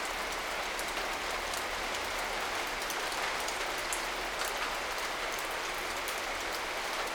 ThinRain_00.wav